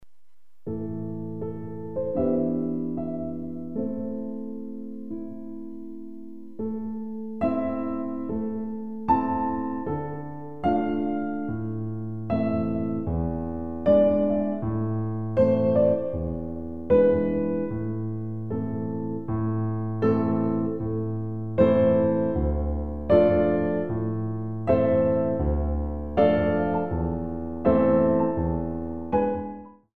All piano CD for Pre- Ballet classes.
2/4 Accent 2